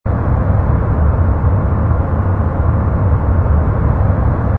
ambience_equip_ground_smaller.wav